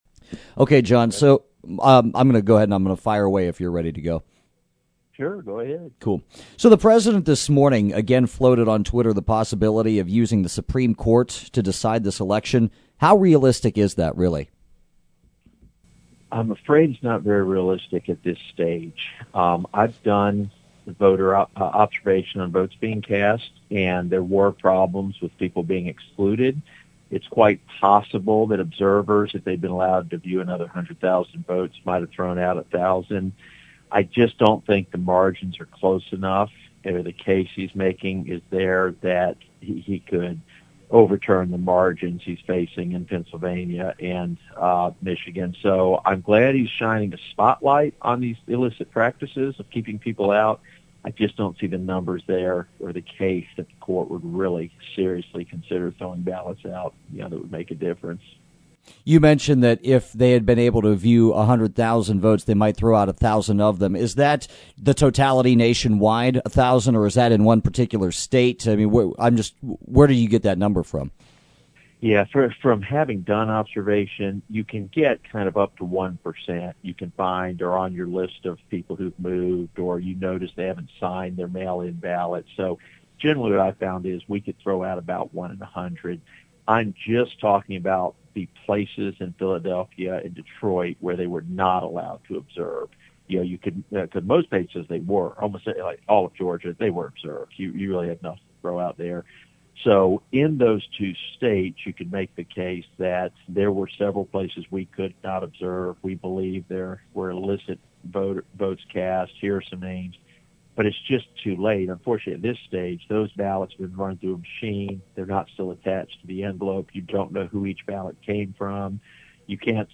The complete interview